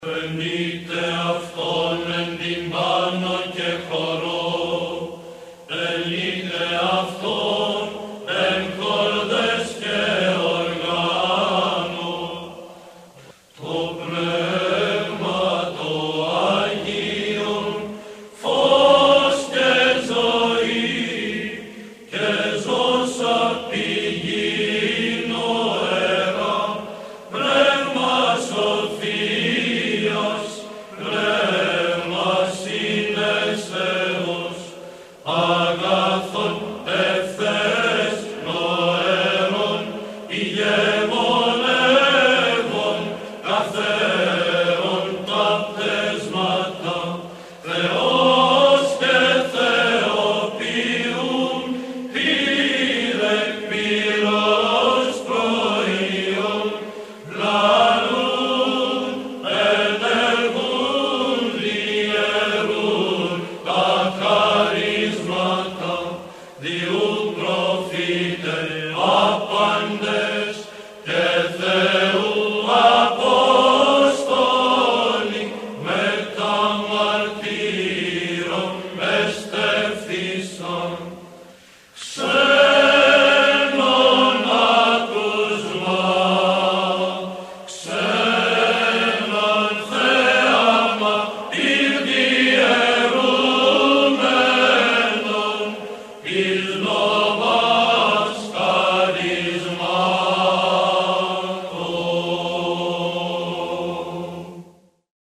Το Πνεύμα το Άγιον – ψάλλει η Χορωδία του Πανελληνίου Συνδέσμου Ιεροψαλτών «Ρωμανός ο Μελωδός και Ιωάννης ο Δαμασκηνός